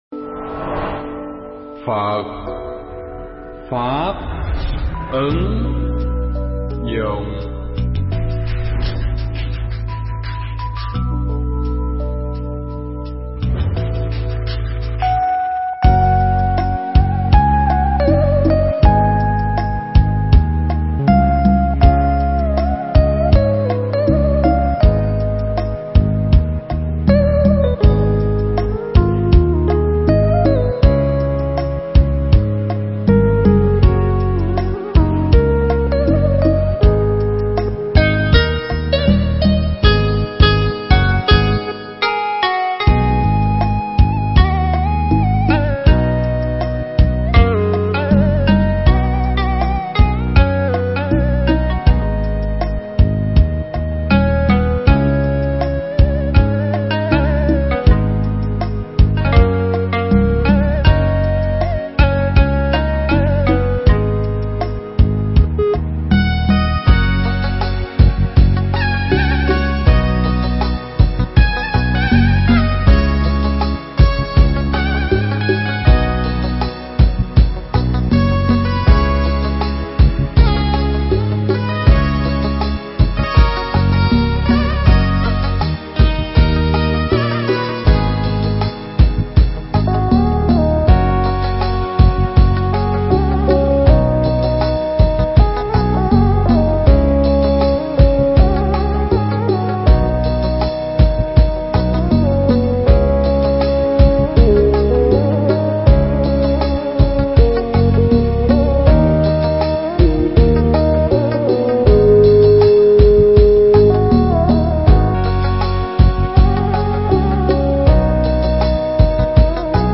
Mp3 Pháp Thoại Ước Nguyện Đầu Năm
Giảng tại Tu Viện Tường Vân (Bính Chánh, HCM)